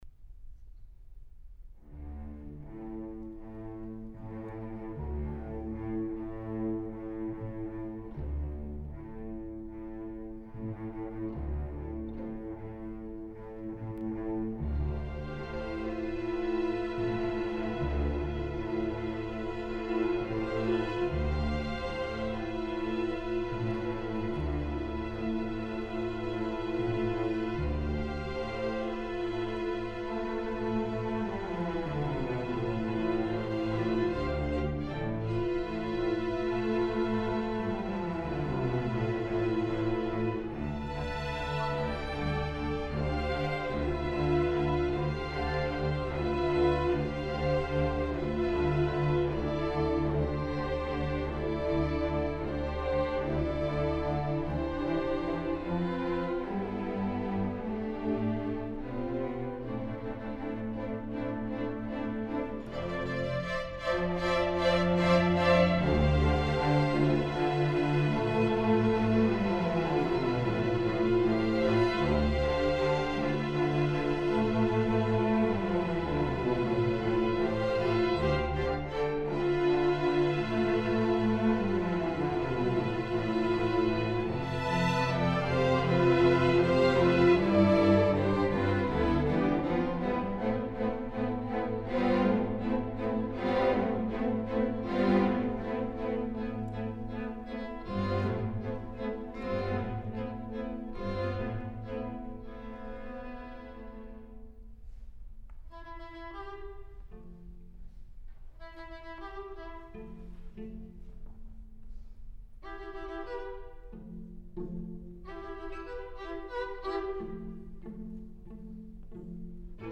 for String Orchestra (2015)
A solo cello looks up to the celestial lights in wonderment.
A quiet ending speaks to the stillness that remains today.